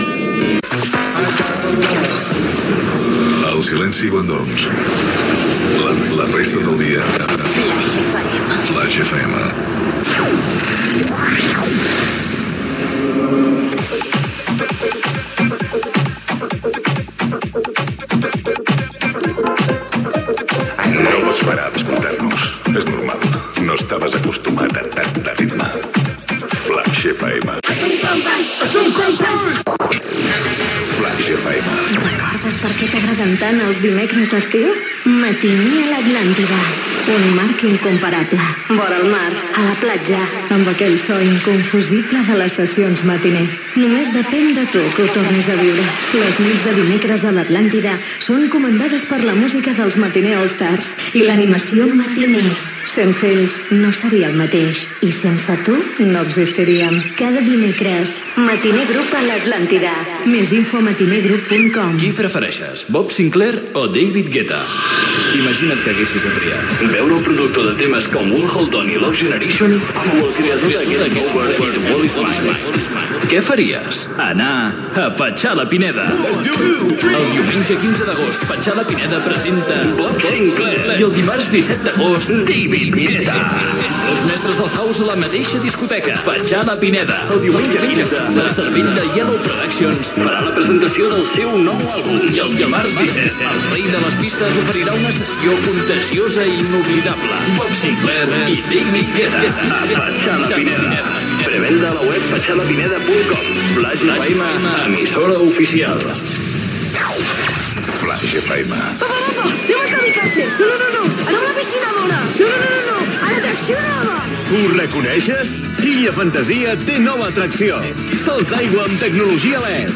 Indicatiu de la ràdio (Miquel Calçada), publicitat, recopilatori "Flaix Summer 2010", publicitat, Flaix és a la xarxa social Twitter